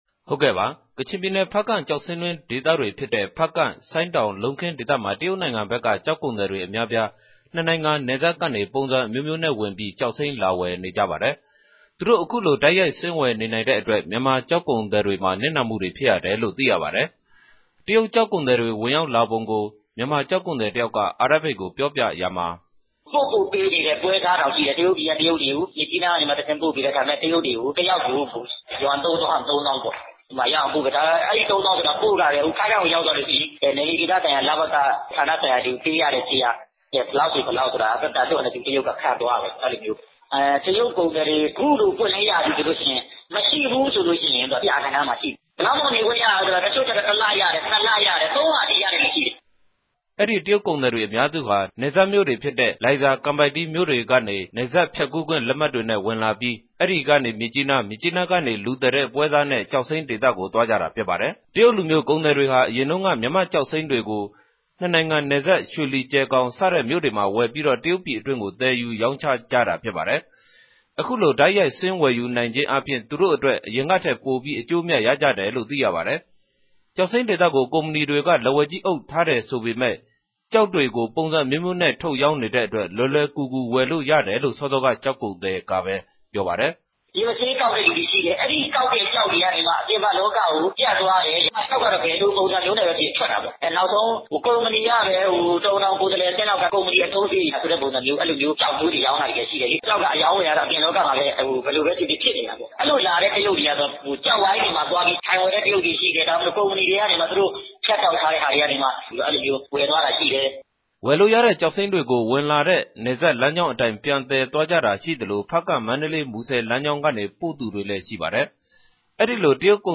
သတင်းပေးပိုႛခဵက်။